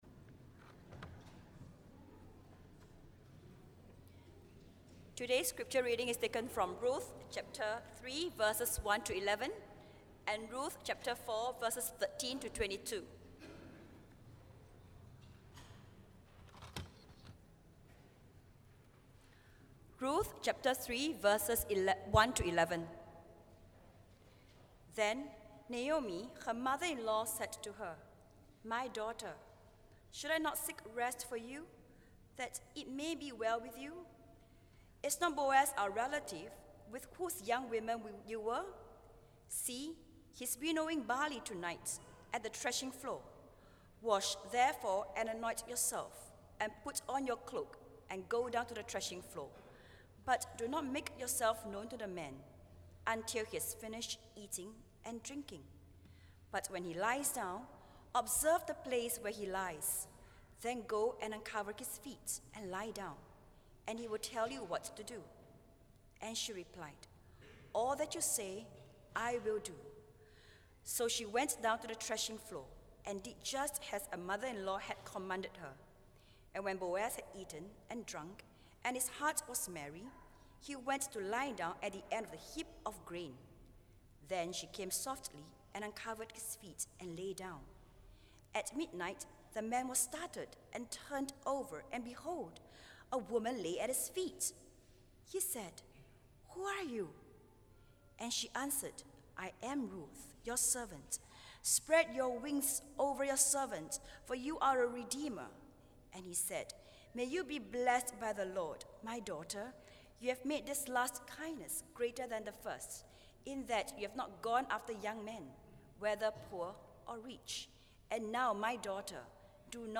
Reflection: What are some situations in our lives that this sermon challenges us to seize the opportunities?